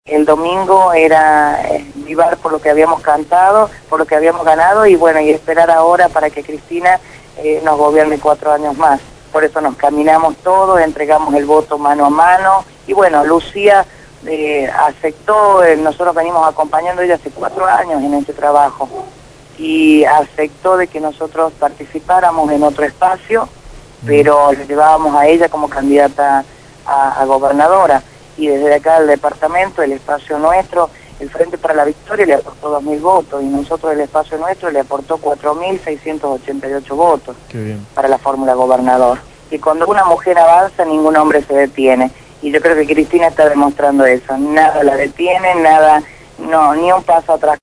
Natalia Soria fue entrevistada en el programa «Sin Zonceras» (Martes de 20 a 22 hs.) por Radio Gráfica y compartió su alegría por el triunfo electoral, «Terminamos con 20 años de un gobierno que nos a impedido crecer, en el departamento hace 20 años que no ingresa una fábrica» declaró.